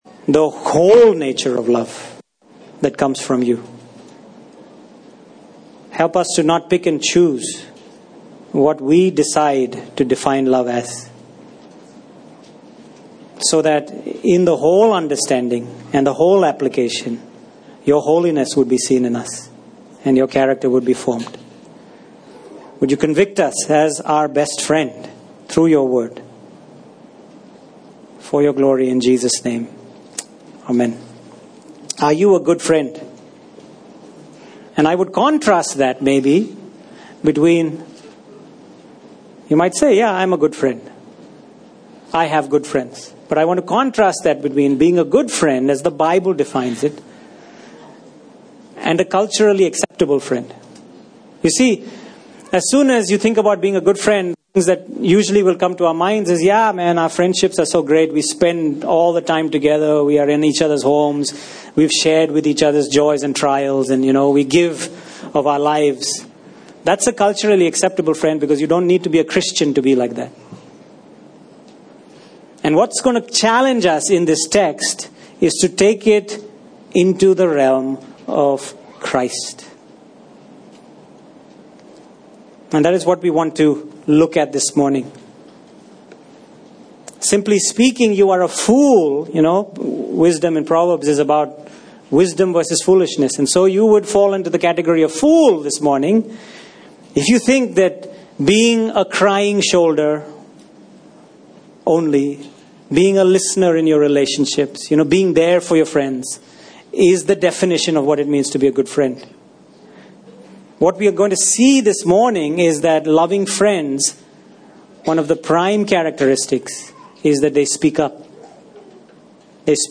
Passage: Proverbs 27 Service Type: Sunday Morning